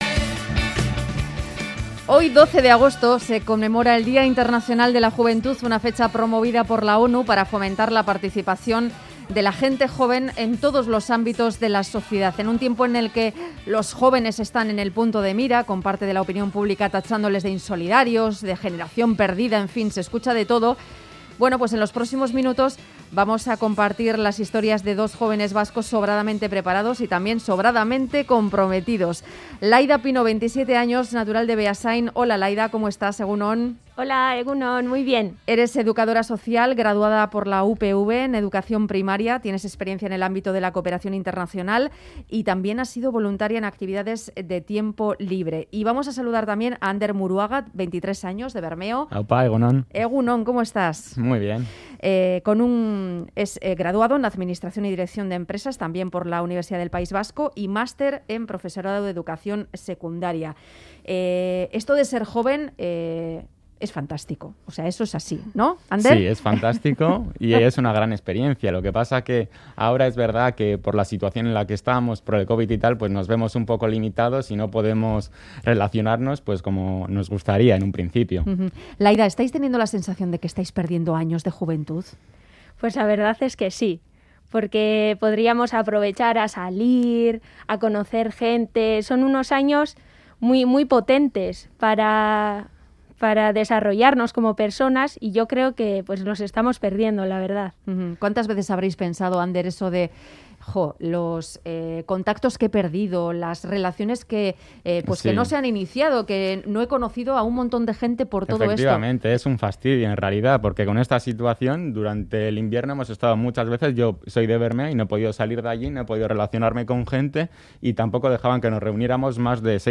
Hablamos con ellos en Boulevard de Radio Euskadi.